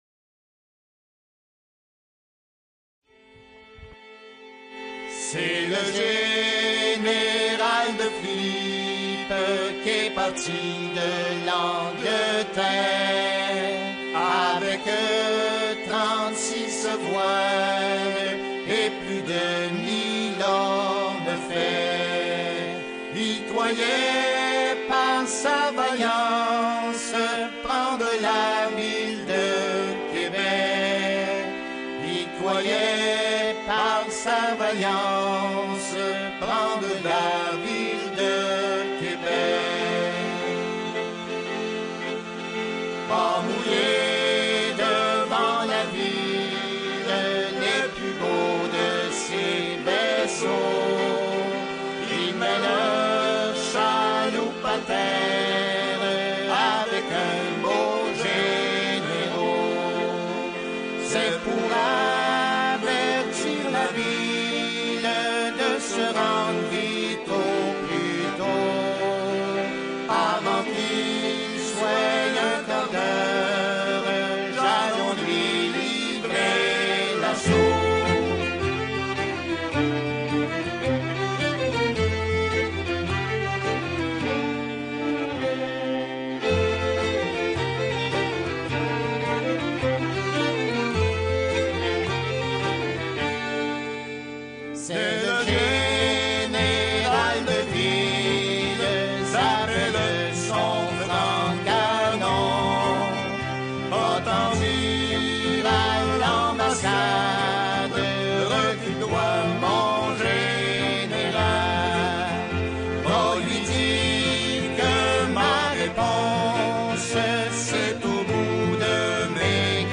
complainte